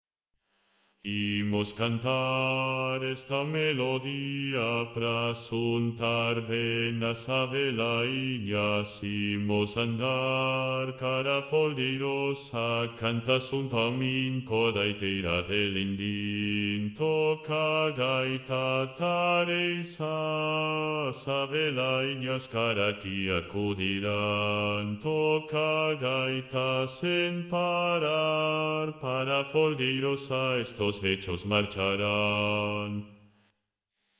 Baixo.mp3